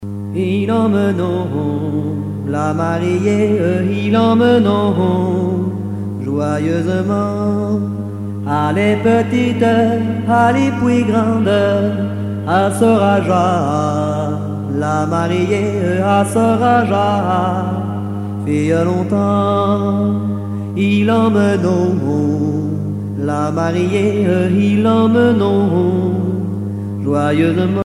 Chants brefs - De noces
Pièce musicale éditée